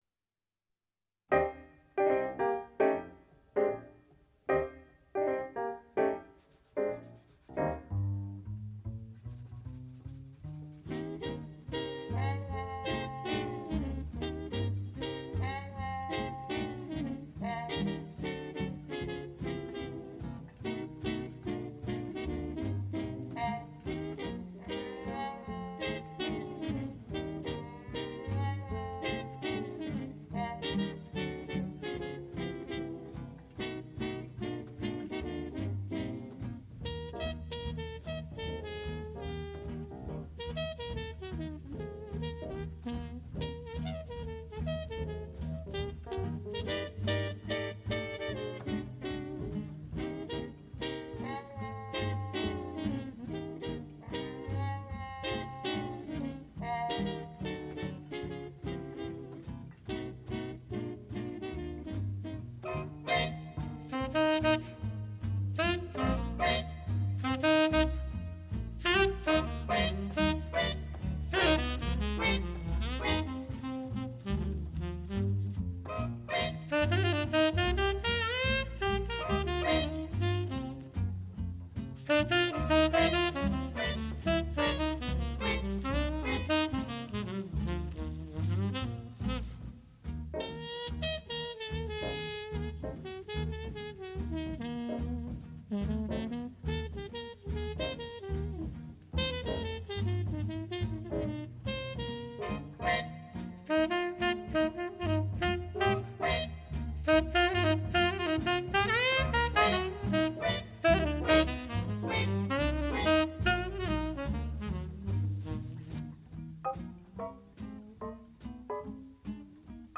Heimat/Home / O / Opus 78 / Konzertmitschnitte